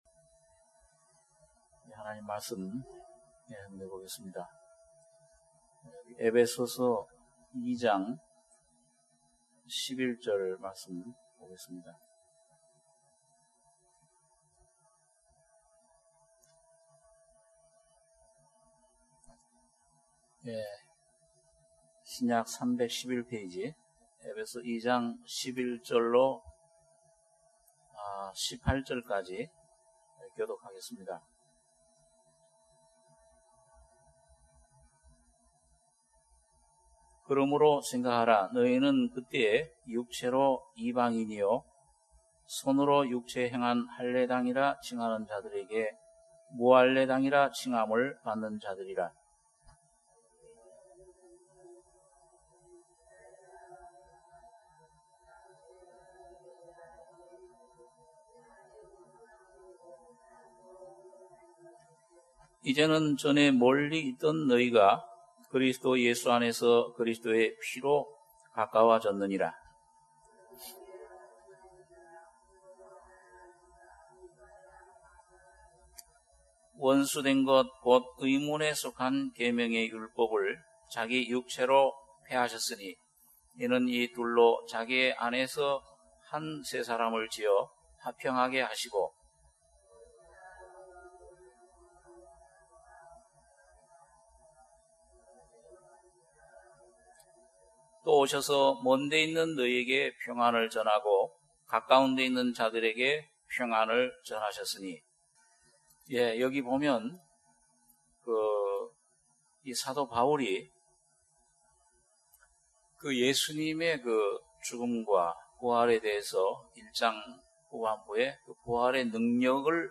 주일예배 - 에베소서 2장 11절~18절 주일2부